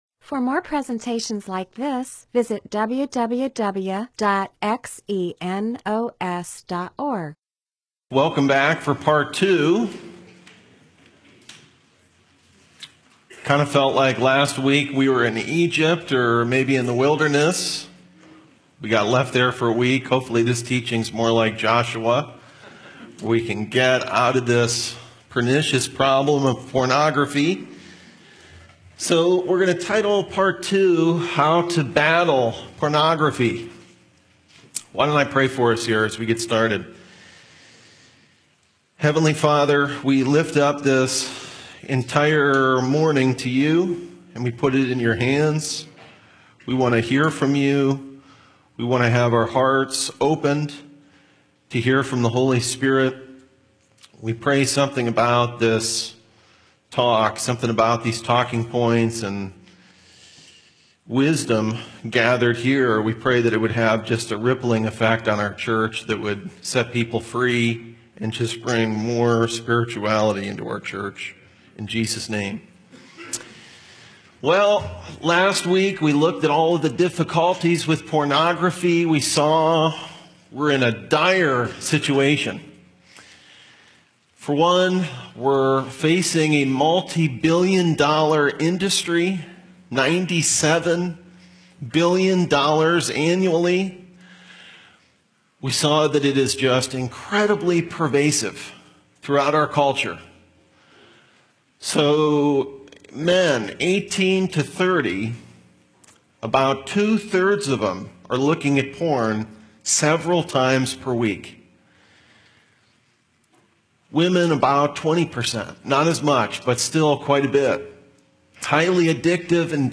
MP4/M4A audio recording of a Bible teaching/sermon/presentation about .